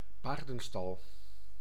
Ääntäminen
Ääntäminen France: IPA: /e.ky.ʁi/ Haettu sana löytyi näillä lähdekielillä: ranska Käännös Ääninäyte Substantiivit 1. stal Muut/tuntemattomat 2. paardenstal Suku: f .